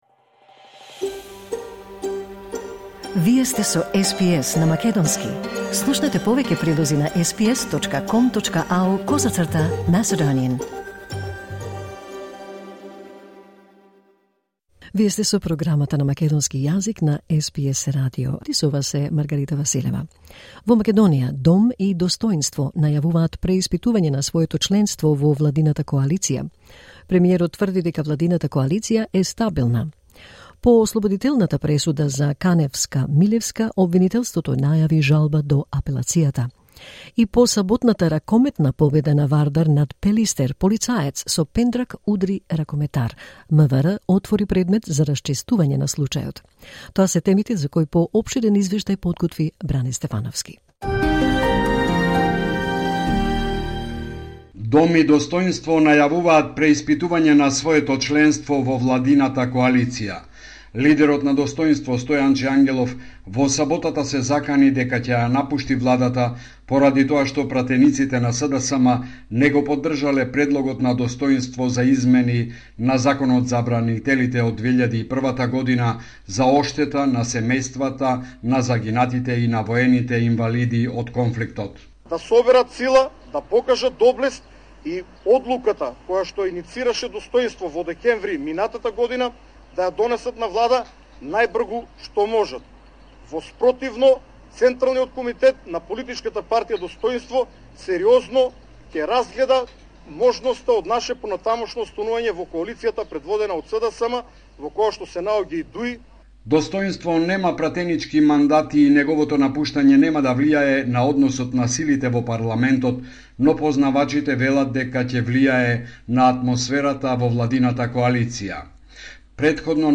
Homeland Report in Macedonian 15 May 2023